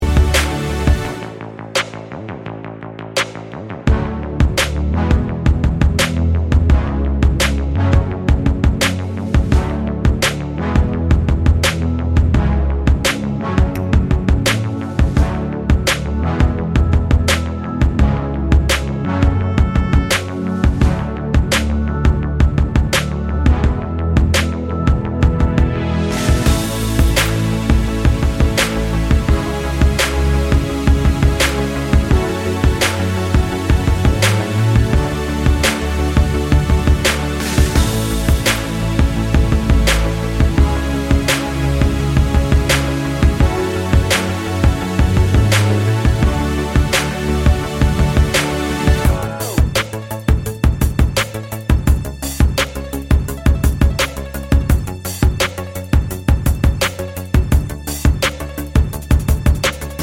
No Backing Vocals & No Vocoder Pop (2020s) 2:57 Buy £1.50